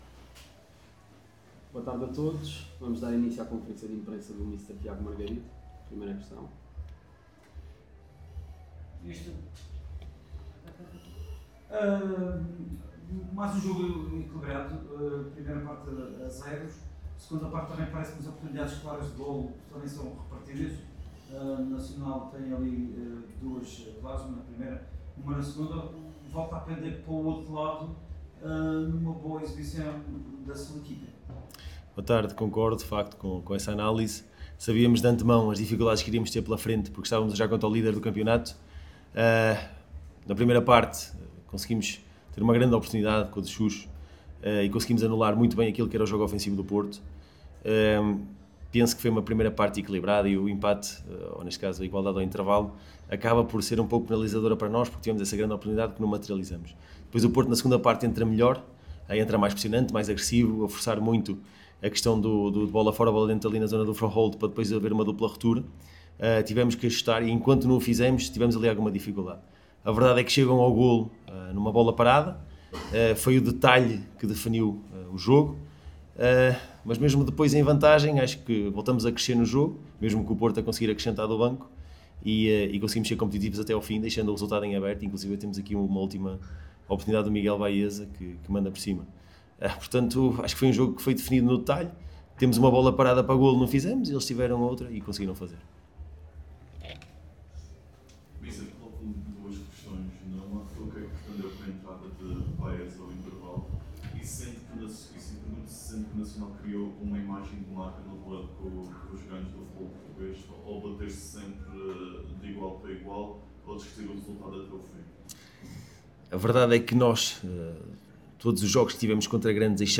Na conferência de imprensa realizada no final do encontro para a 22.ª jornada da Liga Portugal Betclic